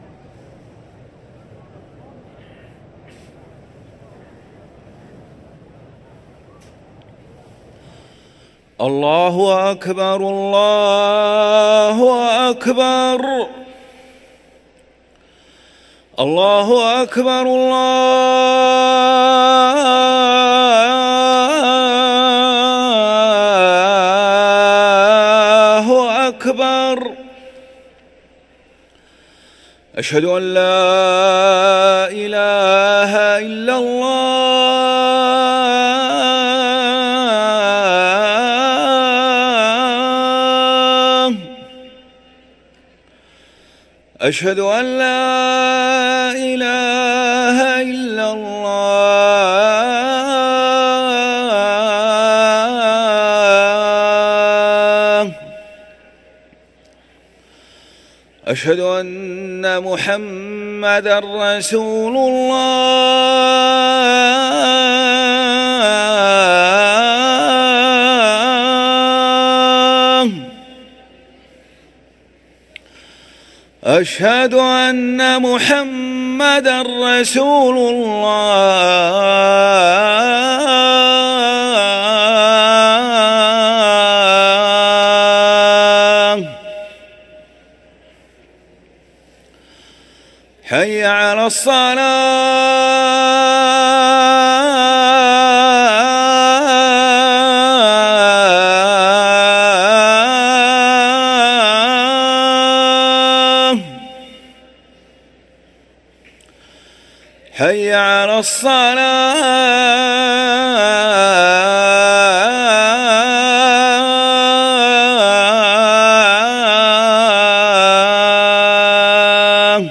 أذان العشاء
ركن الأذان